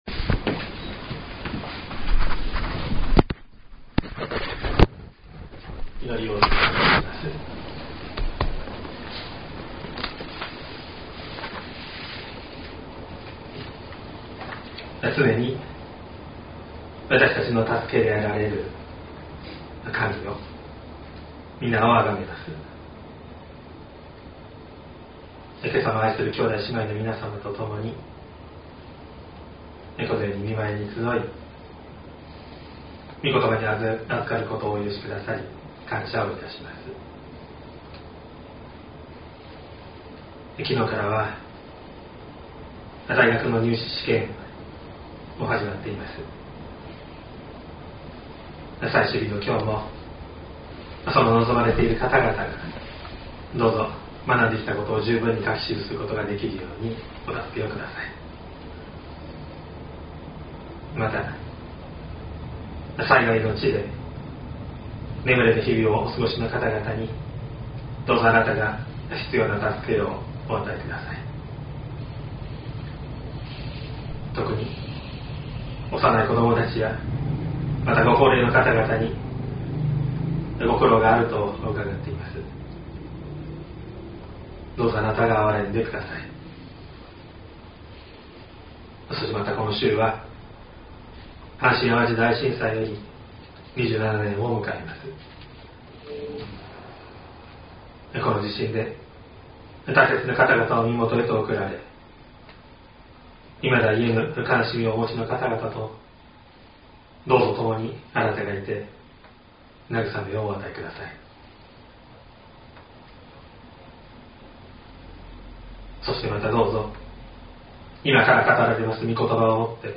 2024年01月14日朝の礼拝「旅の基本的な備え」西谷教会
説教アーカイブ。
音声ファイル 礼拝説教を録音した音声ファイルを公開しています。